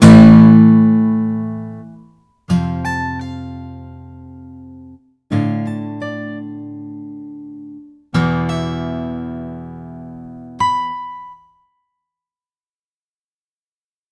Zunächst ist das Ausganspattern zu hören, danach dann die Variationen, welche mit ChordPotion erzeugt wurden.
Gitarre:
gitarre-midi-pur.mp3